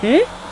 Hmmm Sound Effect
Download a high-quality hmmm sound effect.
hmmm.mp3